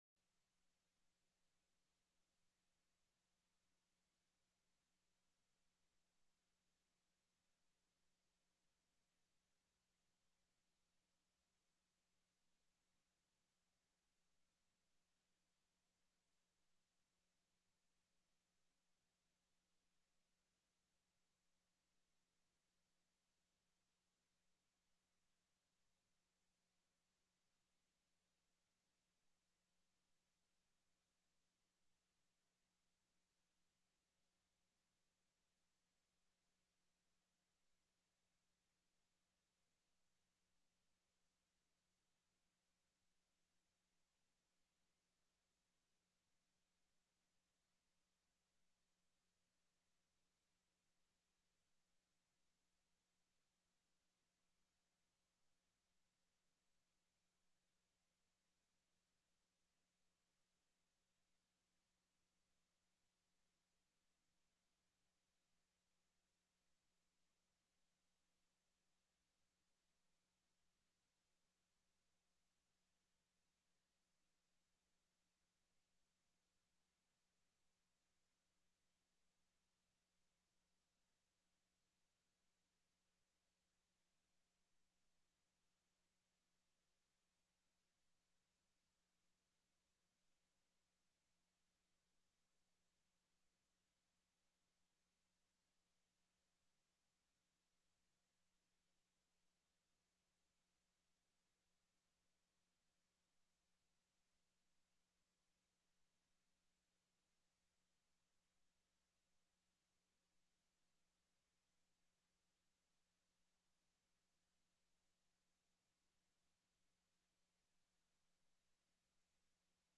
03/16/2021 09:00 AM Senate FINANCE
The audio recordings are captured by our records offices as the official record of the meeting and will have more accurate timestamps.
Spring Revenue Update TELECONFERENCED
Commissioner Lucinda Mahoney, Department of Revenue